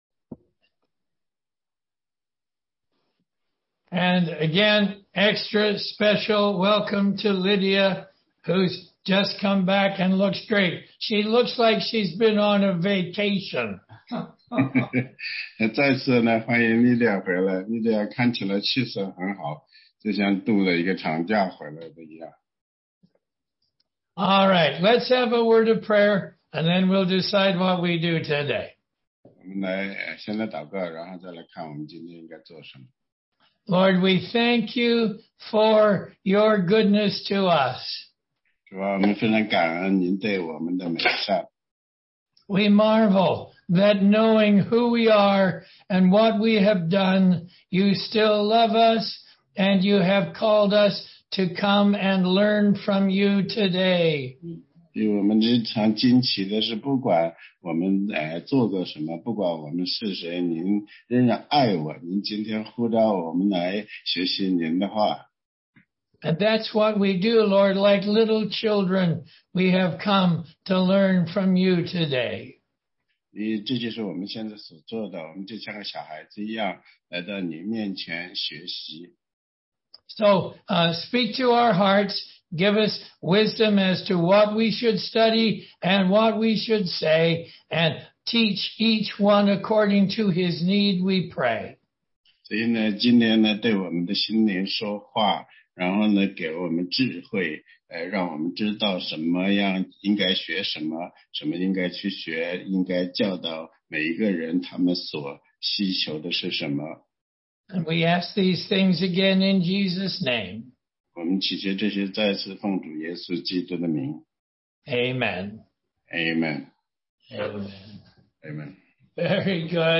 答疑课程